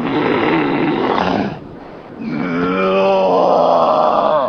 zombie.ogg